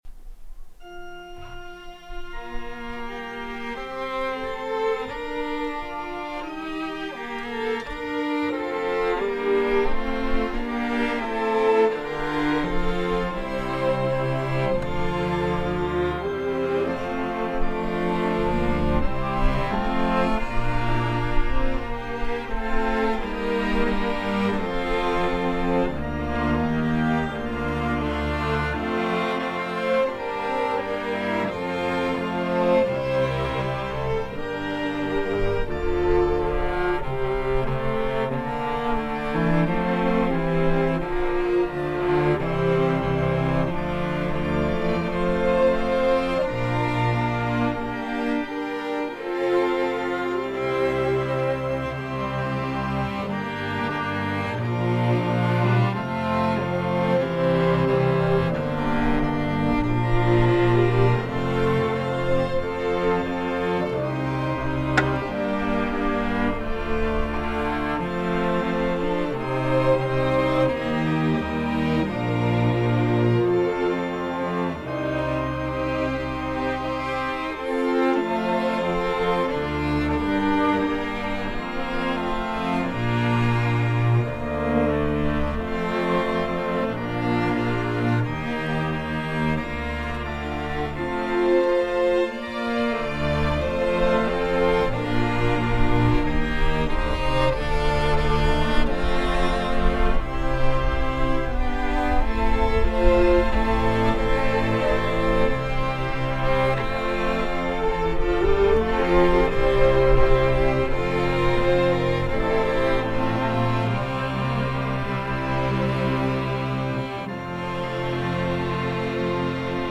“Jesu, redemptor omnium” για Ορχήστρα Εγχόρδων (live)